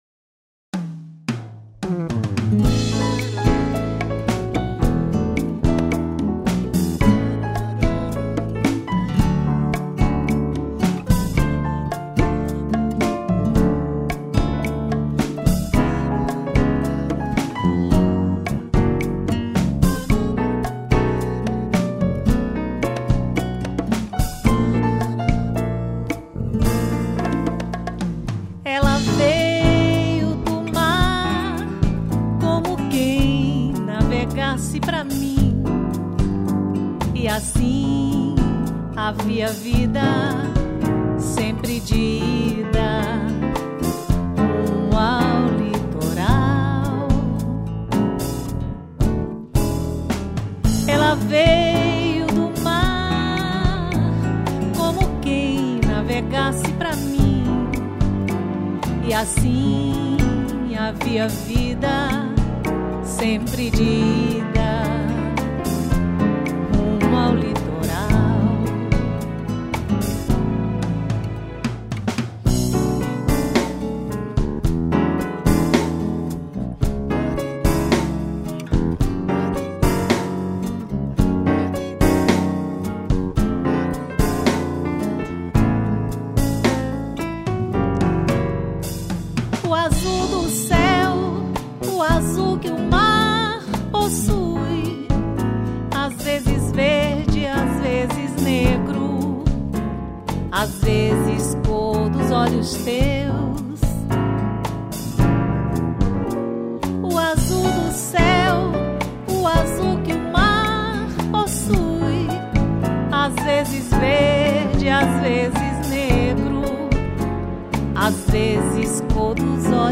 3098   07:05:00   Faixa: 11    Bolero
Baixo Elétrico 6